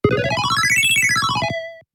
welcome-sound.mp3